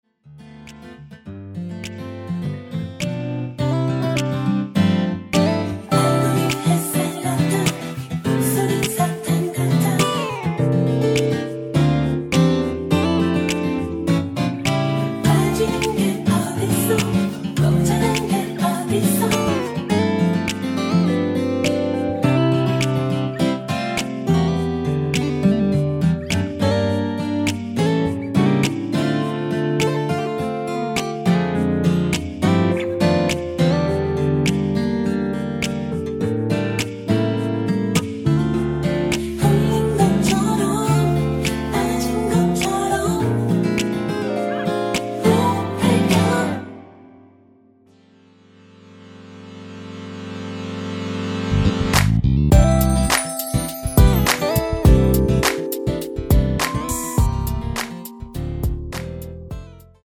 (-2) 내린 코러스 포함된 MR 입니다.(미리듣기 참조)
◈ 곡명 옆 (-1)은 반음 내림, (+1)은 반음 올림 입니다.
앞부분30초, 뒷부분30초씩 편집해서 올려 드리고 있습니다.
중간에 음이 끈어지고 다시 나오는 이유는